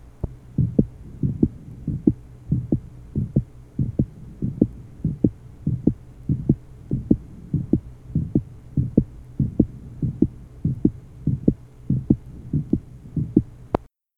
Date 1970 Type Unknown Abnormality Unknown Patient meets Starr's criteria for ball variance. Recording taken for spectrographic analysis.
RSE (Channel A) RUSB (Channel B) RSE (bell) (Channel C) RUSB (bell) (Channel D) %s1 / %s2